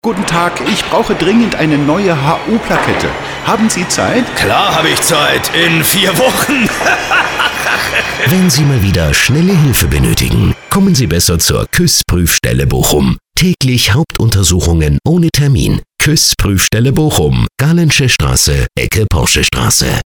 Unsere Radio Spots
Radiospot 1 |